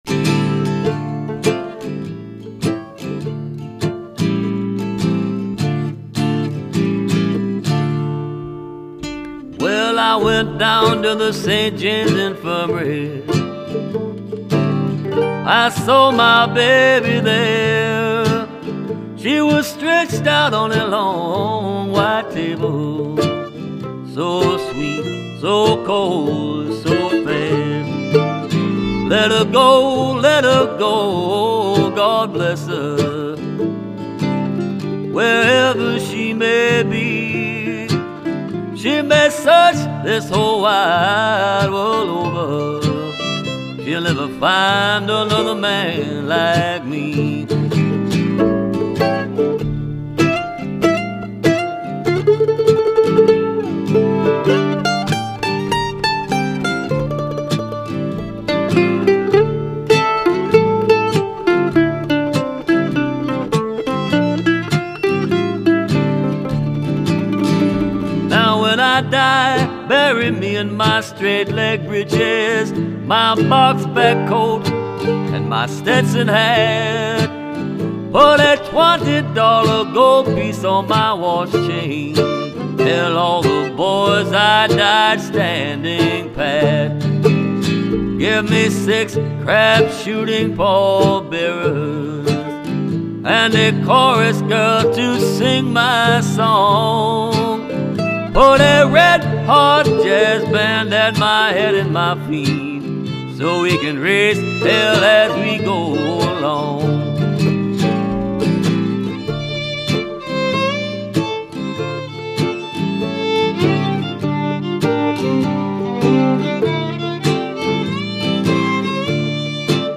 mandolin
fiddle and harmony vocals